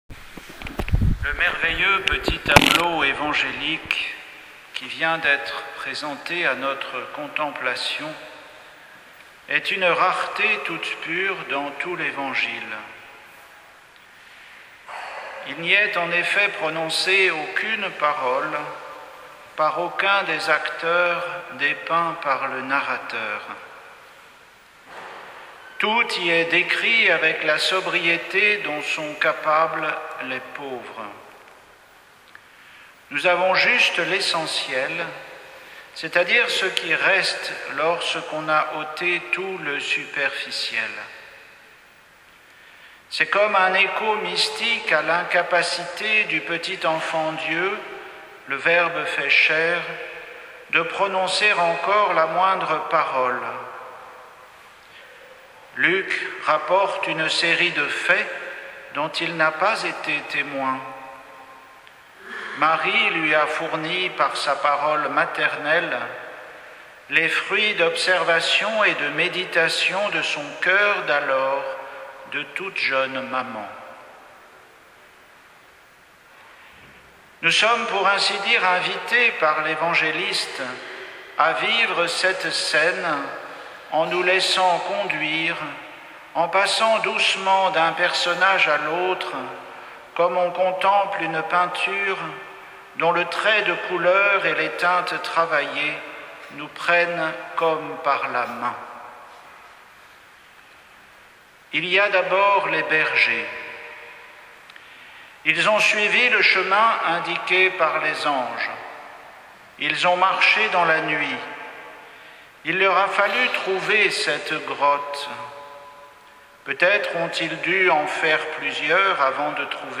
Homélie pour la solennité de la Vierge Marie Mère de Dieu, 1er janvier 2023